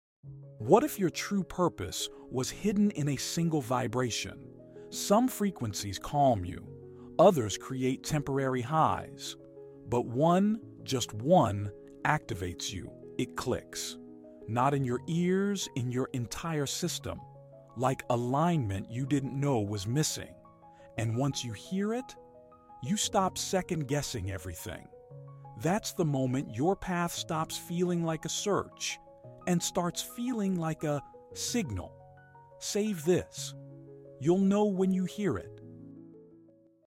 They deliver the full frequency range directly to your nervous system, helping your body relax and reset faster.